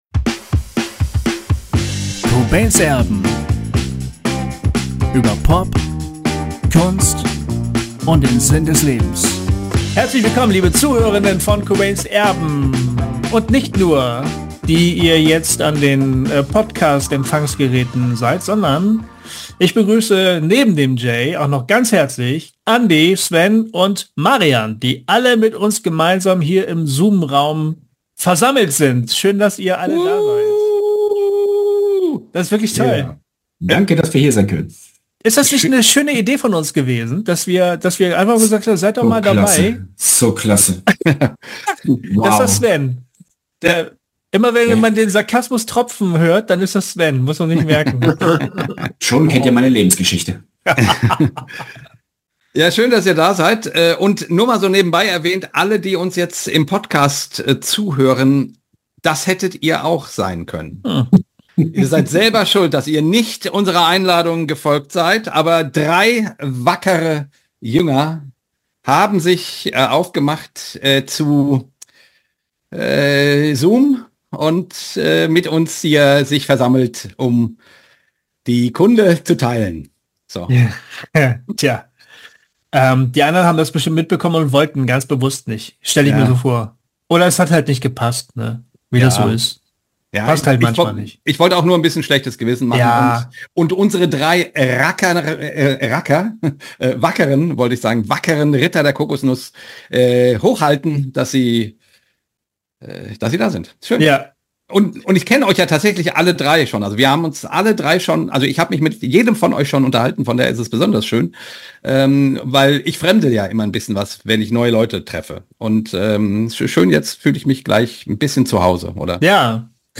Wir haben zurückgeblickt auf die Themen der letzten Staffel und gemeinsam darüber nachgedacht, was uns gefallen hat, was wir besser machen können und welche Themen wir in Zukunft einmal angehen sollten. Ein entspannter, knapp einstündiger Plausch, an dem Ihr nachträglich teilhaben könnt.